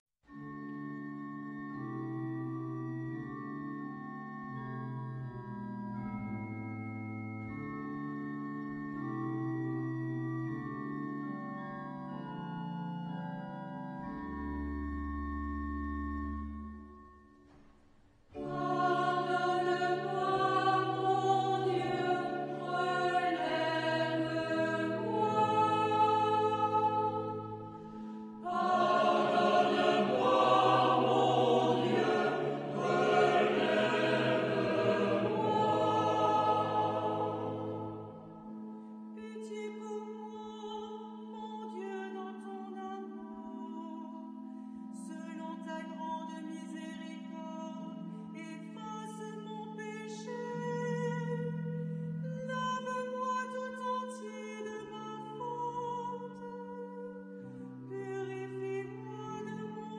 Genre-Style-Forme : Sacré ; Psaume
Type de choeur : SATB  (4 voix mixtes OU unisson )
Instrumentation : Orgue  (1 partie(s) instrumentale(s))
Tonalité : la mineur